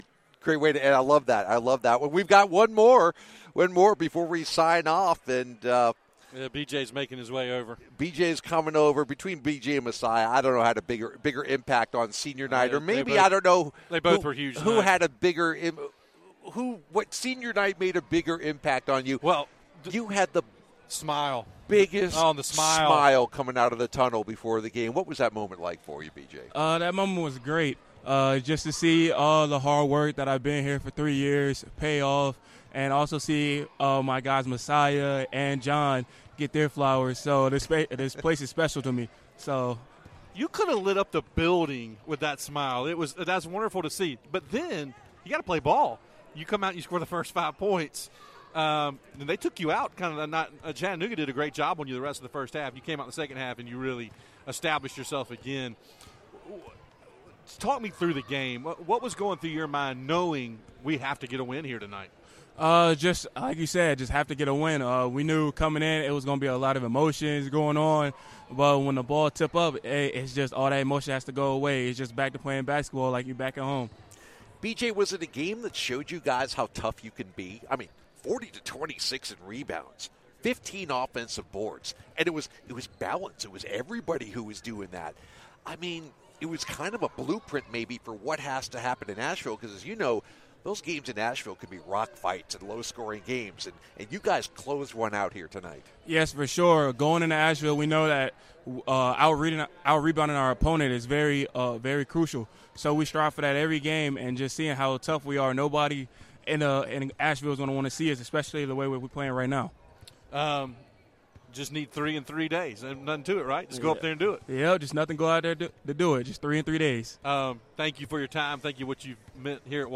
February 26, 2023 Download Listen Now All Categories Postgame Audio All Sports Men's Basketball Women's Basketball Loading More Podcasts...